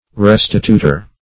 restitutor - definition of restitutor - synonyms, pronunciation, spelling from Free Dictionary